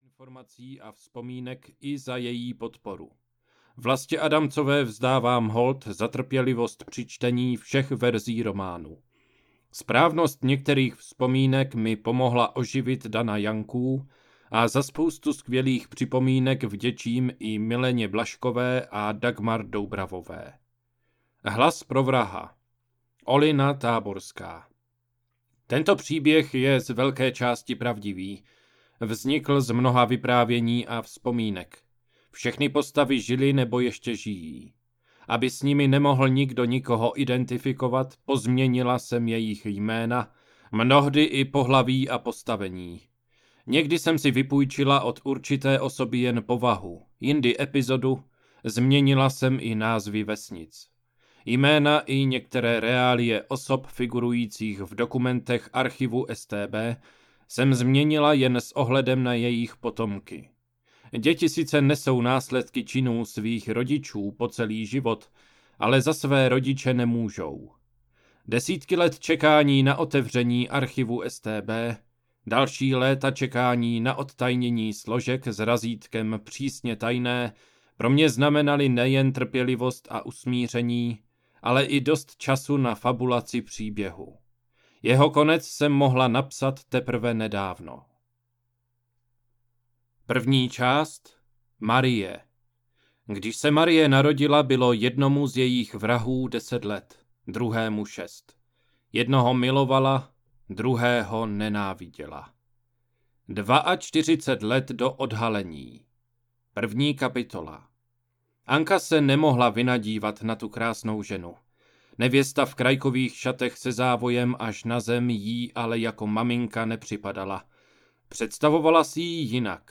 Hlas pro vraha audiokniha
Ukázka z knihy